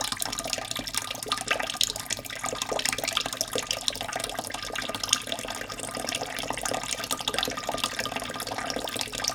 water_dripping_running_02_loop.wav